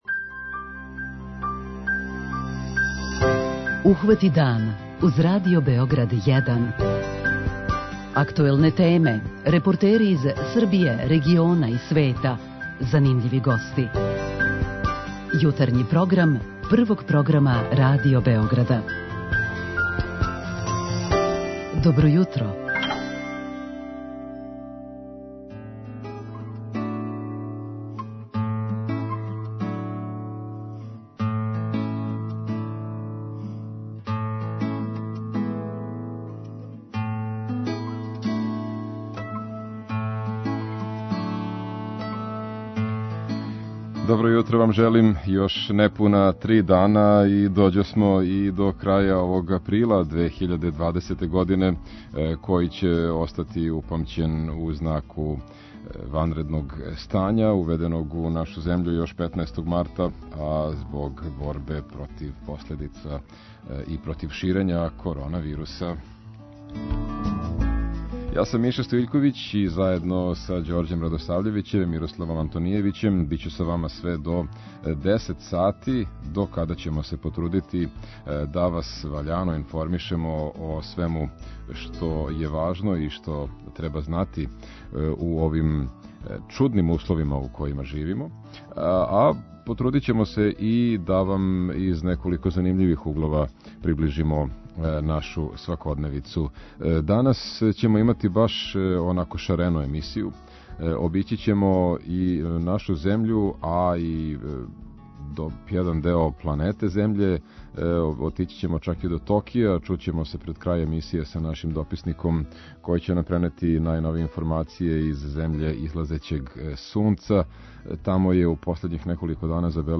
Последњи уторак у априлу обележиће теме - од репортаже из фризерског салона који је поново отворен после месец дана, до укључења нашег дописника из Јапана где се повећава број новозаражених од Ковида-19.